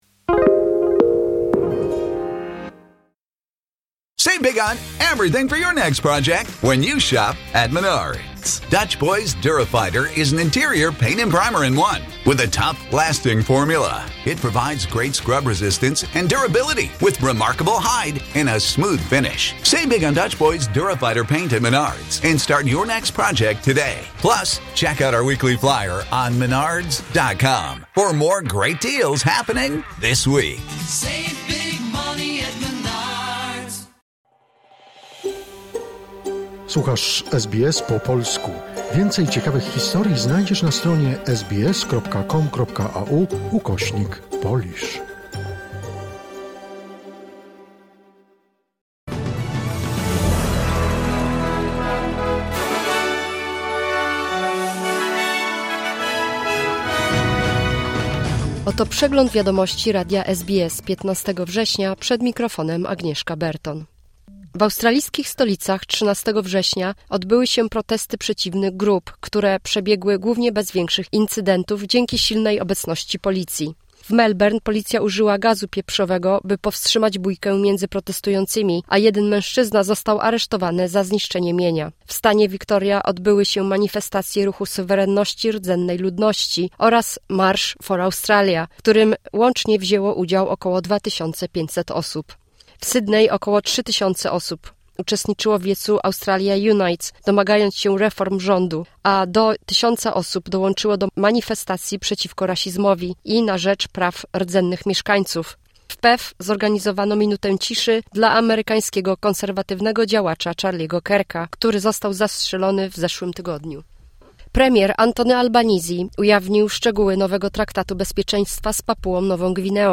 Wiadomości 15 września SBS News Flash